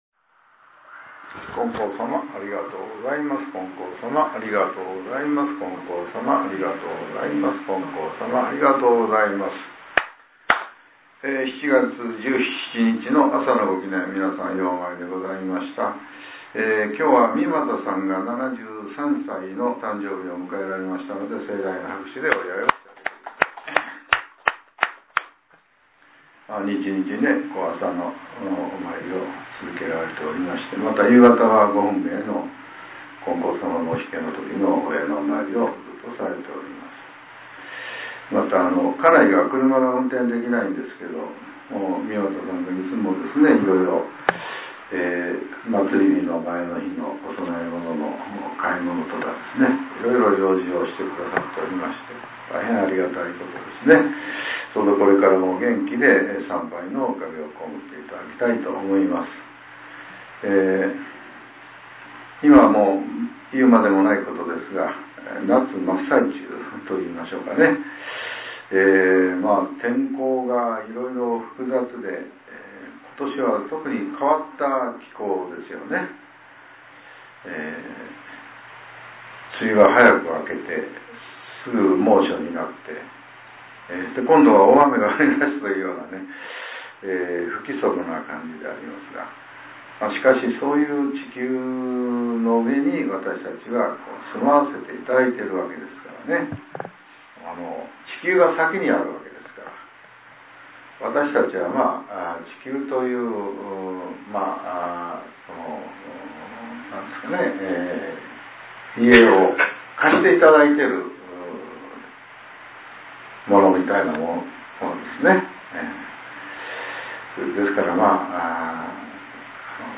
令和７年７月１７日（朝）のお話が、音声ブログとして更新させれています。